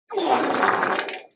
Sound Effects
Wet Fart Walk